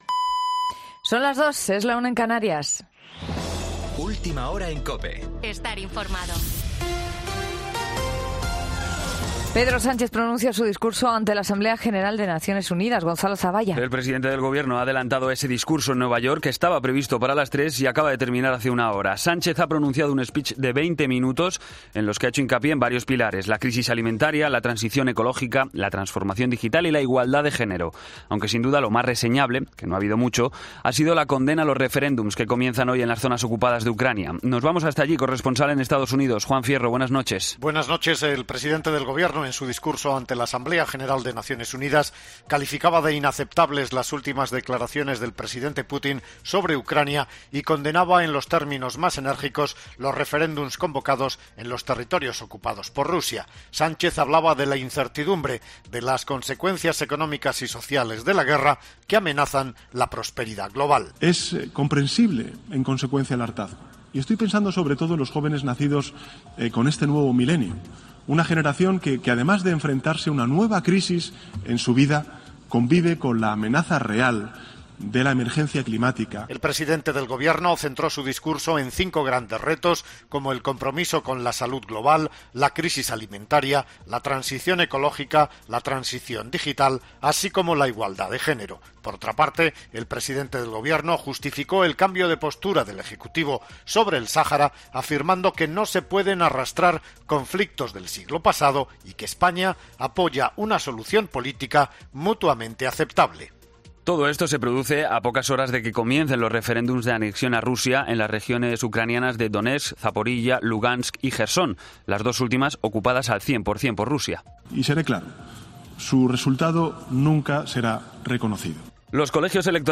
Boletín de noticias COPE del 23 de septiembre a las 02:00 hora